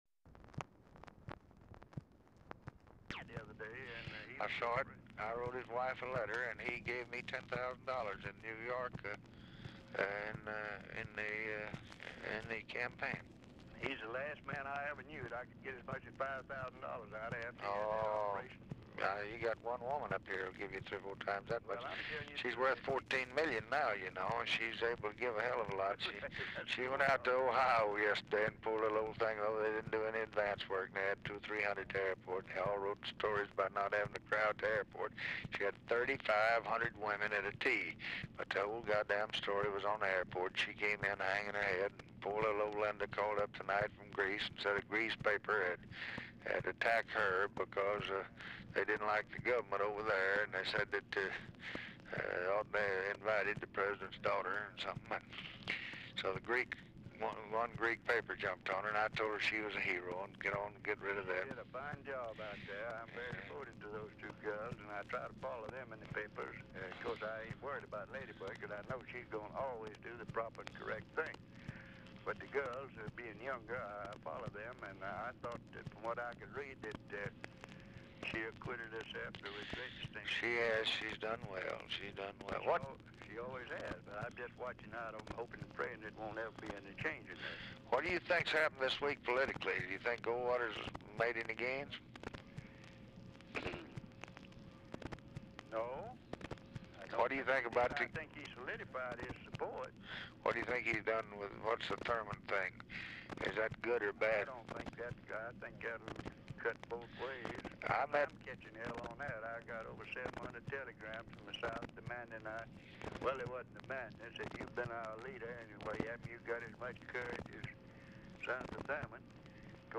Telephone conversation # 5607, sound recording, LBJ and RICHARD RUSSELL, 9/18/1964, 7:54PM
Format Dictation belt
Location Of Speaker 1 Oval Office or unknown location